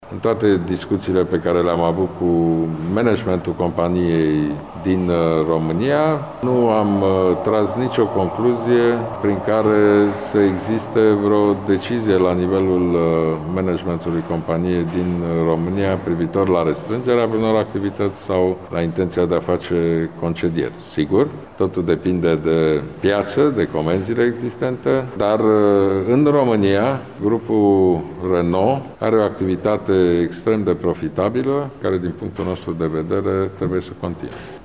Prezent la Tîrgu Mureș, premierul României, Ludovic Orban, a declarat că din toate discuţiile avute cu managementul companiei din România nu a reieșit că ar exista vreo decizie privitor la restrângerea vreunor activităţi.